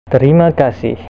speaker.gif (931 bytes) Click on the word to hear it pronounced.